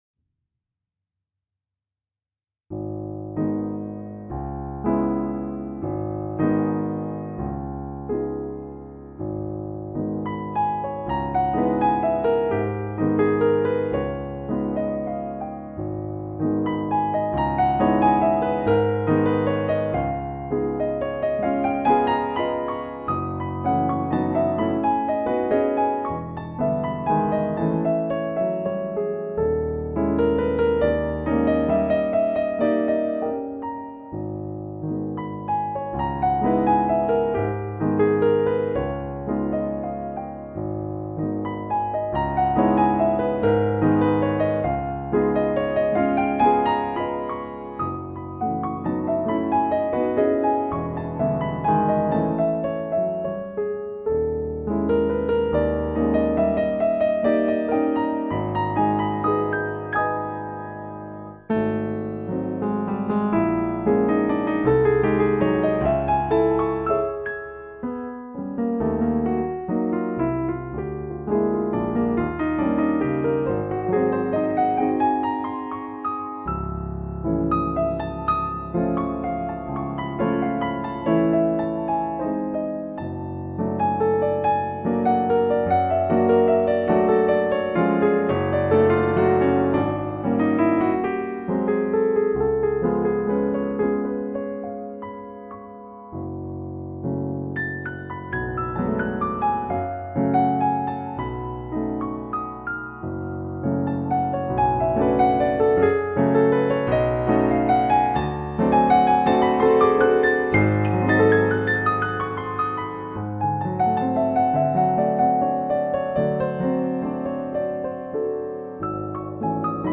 Here are recordings (mp3 files) in which I play eight of my piano compositions.
Waltz, composed in 1995, is one of several I have written. This one is quite dreamy and wistful.